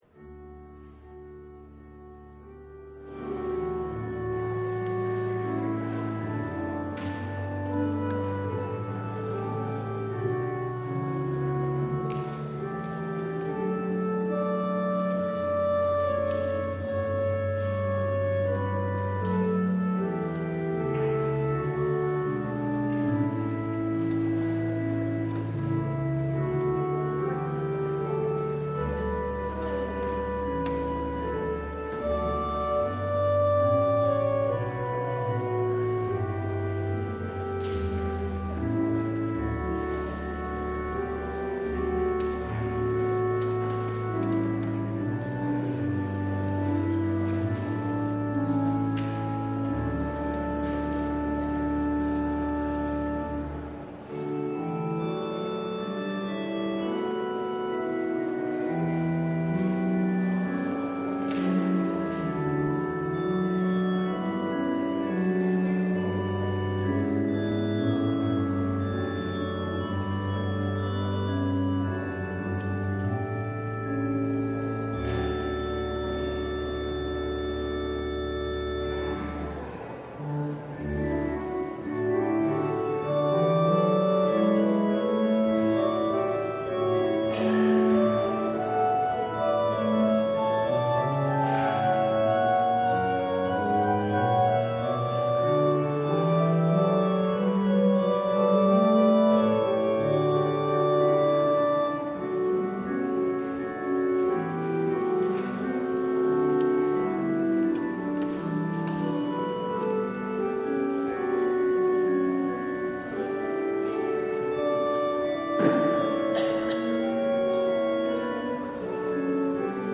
LIVE Morning Worship Service - The Prophets and the Kings: Seeking the Wrong Source